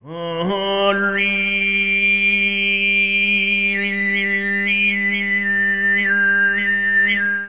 Me singing Sygyt: This image contains the frequencies from about 0-5300 Hz. You can see the scooping of unwanted frequencies and the amplified harmonics of the melody.
sygyt.aiff